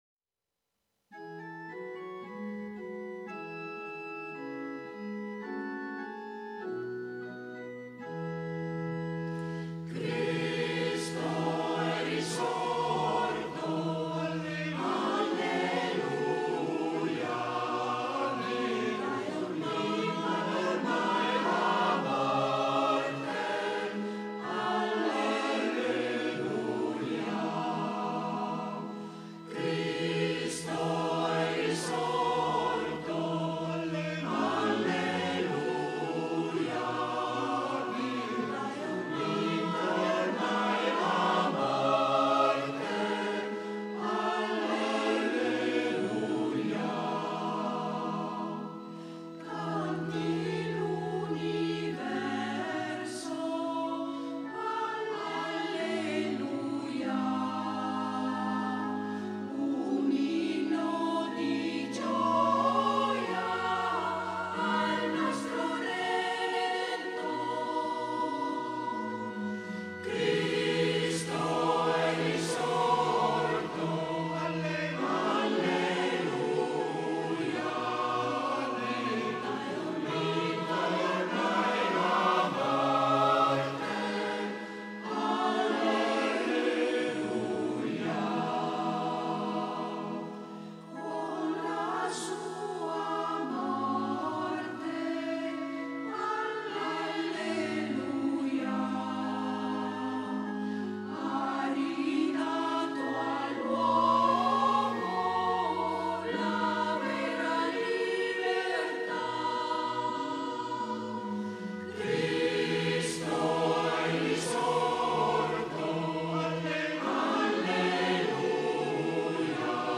Ascoltiamo un canto pasquale del coro,